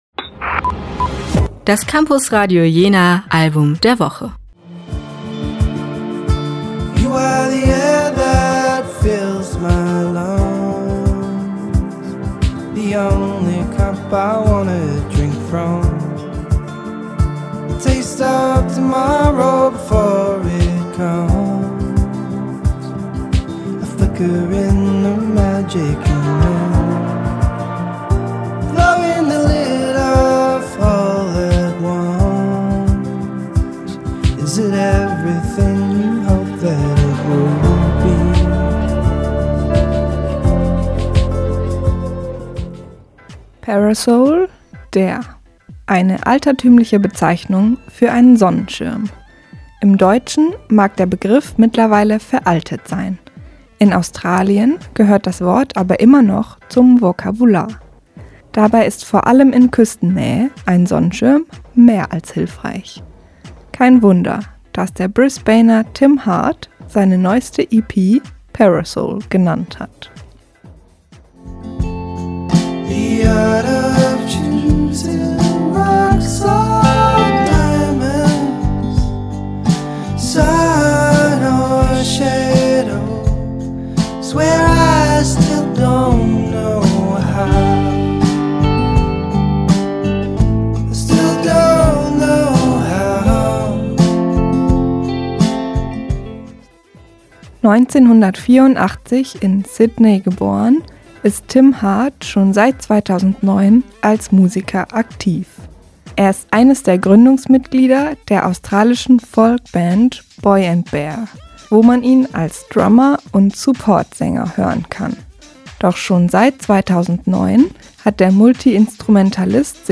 Indie-Folks